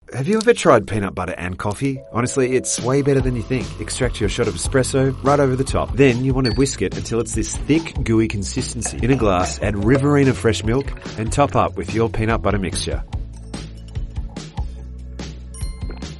Programas de televisão
Olá, sou uma dubladora profissional de inglês australiano com uma entrega calorosa, clara e versátil.
Inclui um microfone Rode NT1-A e uma interface de áudio, tudo instalado em uma cabine à prova de som para garantir excelente qualidade de gravação.